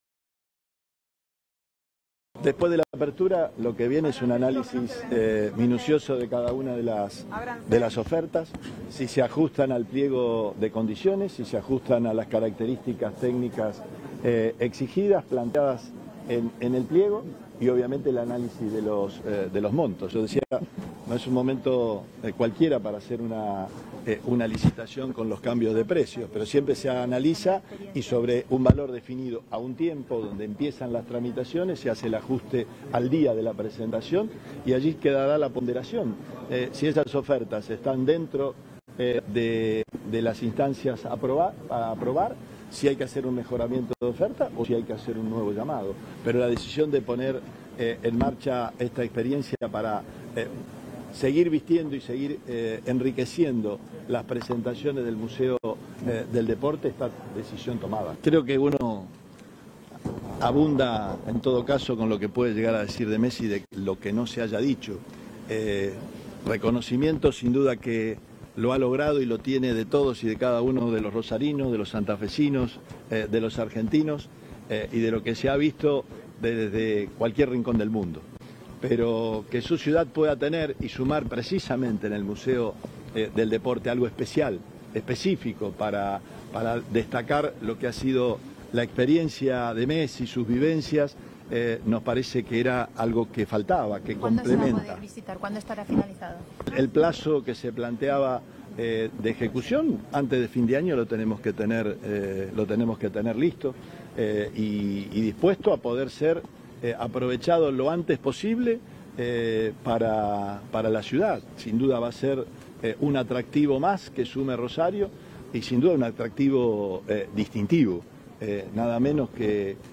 El gobernador Omar Perotti encabezó este viernes el acto de apertura de sobres de la licitación para la puesta en marcha de “Explorando la genialidad de Lionel Messi en una experiencia única”, en el Museo del Deporte Santafesino.
Declaraciones Perotti